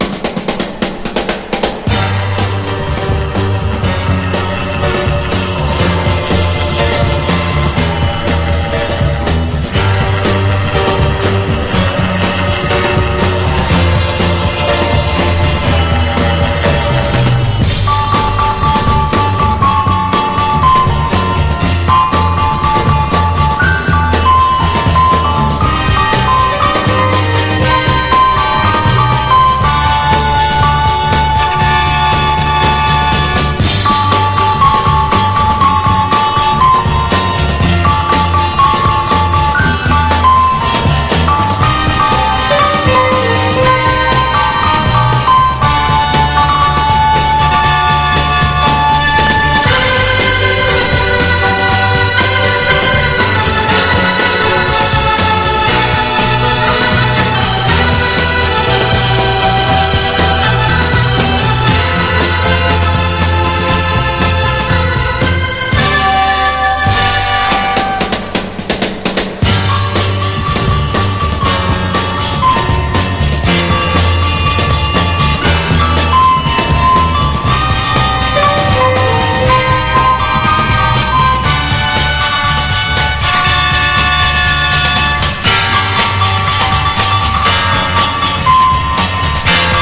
ROCK / SOFTROCK. / SUNSHINE POP (US)
溌溂としたサンシャイン・ポップ〜ソフト・ガレージ！
爽やかなハーモニーが映える
跳ねるようなリズムにのせた
ノーザン調ビートの美メロ
サイケ・ガレージ調のインスト・ナンバー